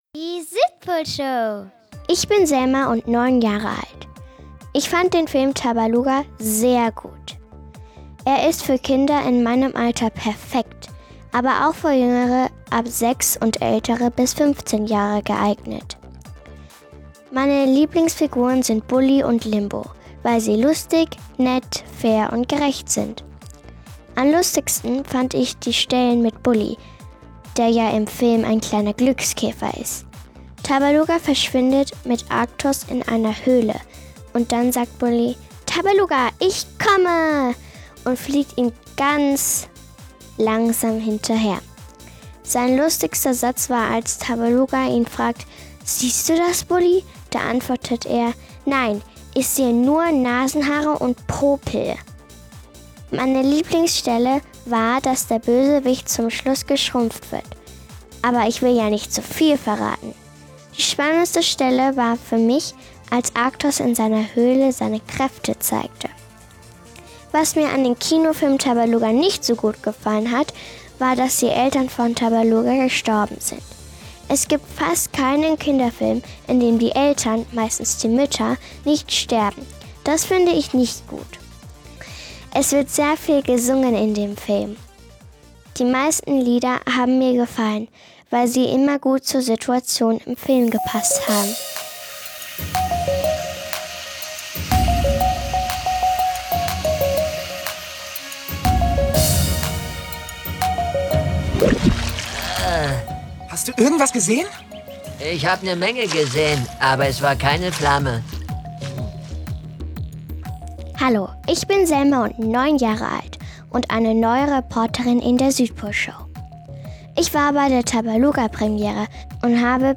Die Südpolreporter waren bei der Premiere und haben Bully zu seiner Synchronrolle im Tabaluga Film befragt. Die Rechte der Film O-Töne liegen bei Sony Pictures Entertainment – DVD „Tabaluga".